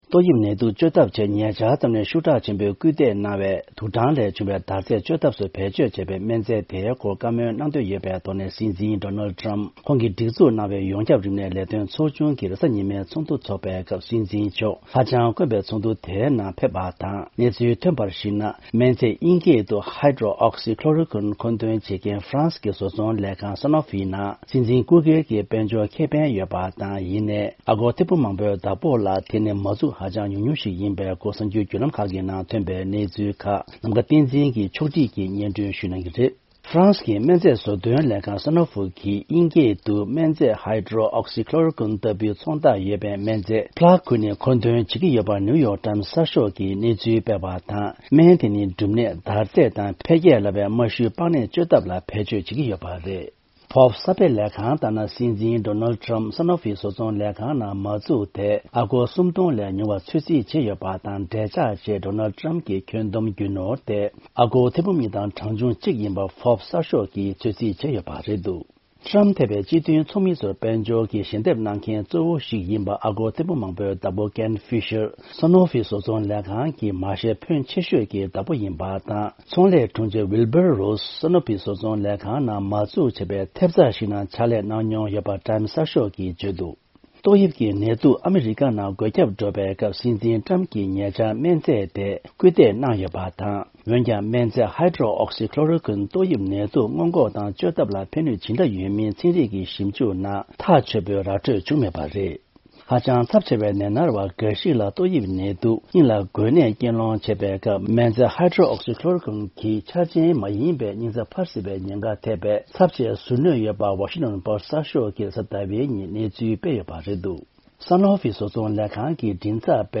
ཕབ་སྒྱུར་དང་ སྙན་སྒྲོན་ཞུ་ཡི་རེད།།